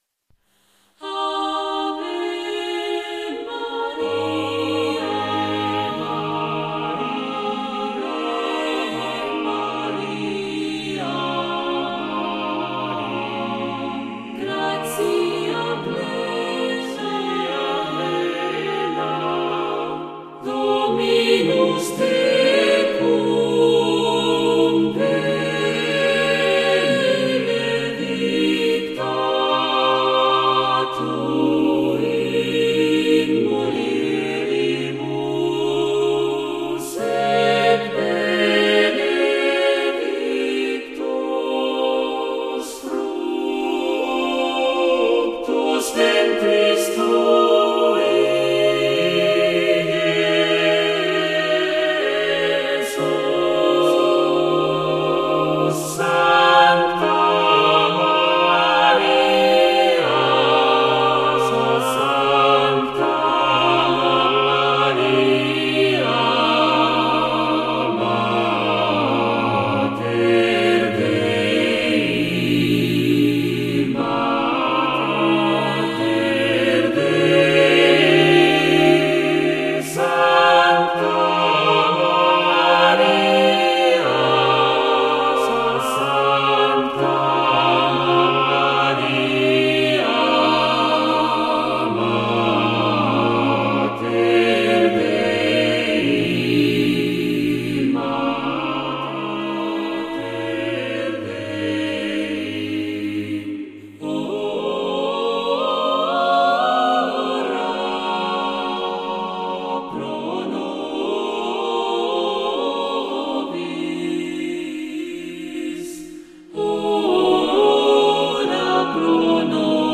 Obra Coral de música sacra para coro mixto SATB con divisi.
Sacred Choral song per mixted choir SATB with divisi.
Formato: SATB (divisi)